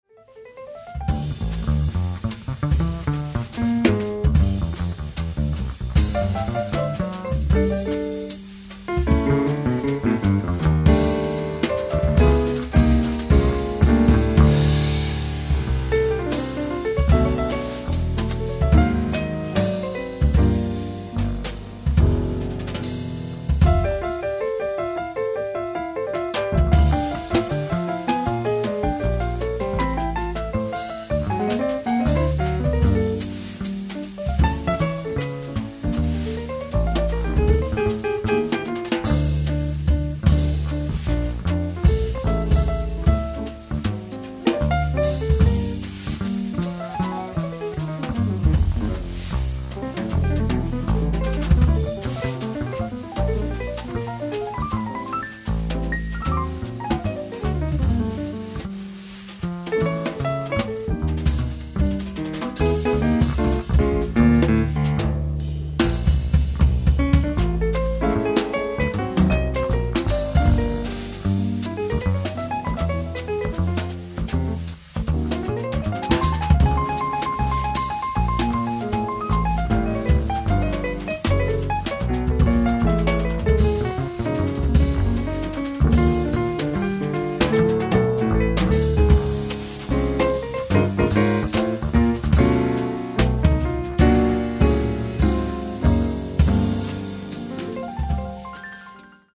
ライブ・アット・バレッタ、マルタ 07/21/2018
※試聴用に実際より音質を落としています。